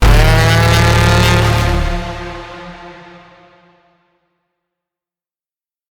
Free SFX sound effect: Scan Denied.
Scan Denied
Scan Denied.mp3